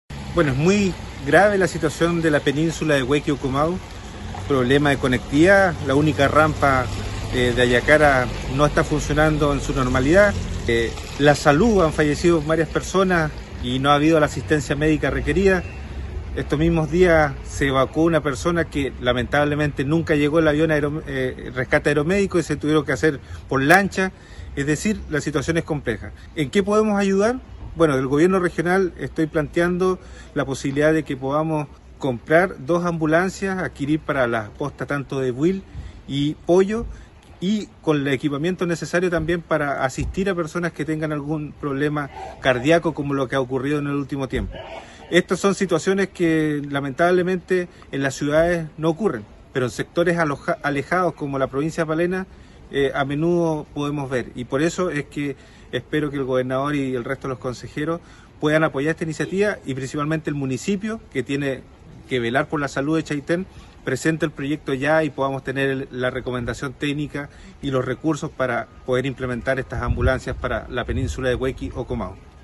El presidente de la comisión provincial Palena del Consejo Regional Los Lagos, Fernando Hernández, expuso la grave situación que enfrenta la península de Huequi-Comau.